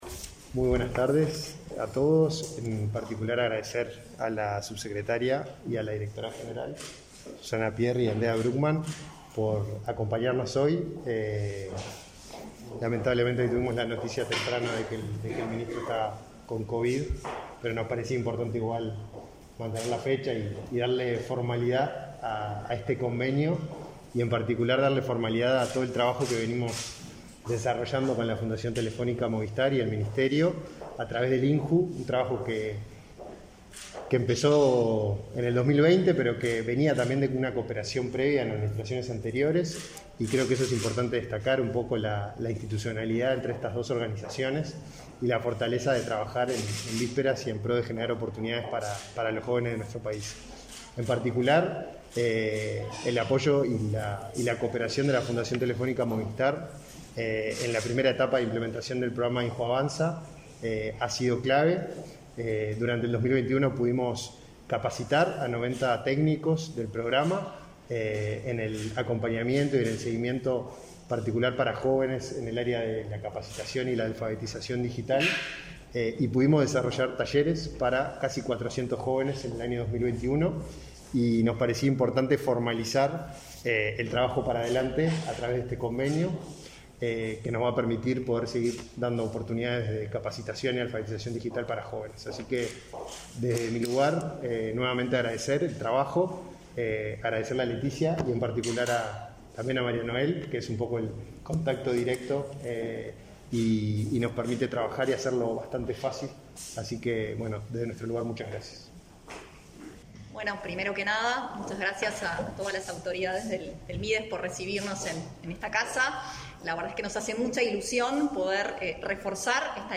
Conferencia de prensa por acuerdo entre el Mides y la Fundación Telefónica